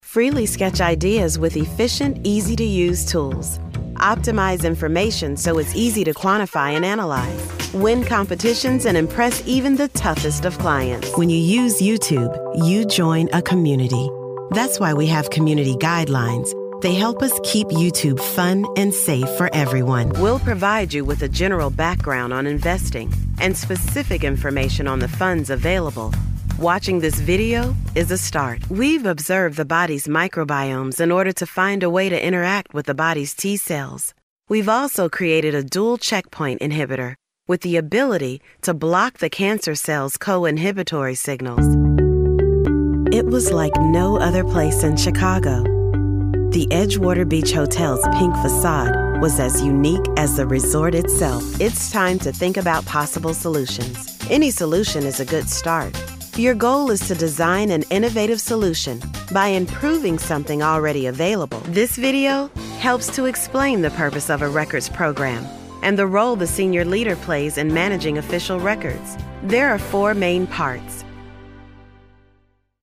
Inglés (Americano)
Llamativo, Seguro, Natural, Suave, Empresarial
E-learning
She works from an amazing home studio with professional equipment.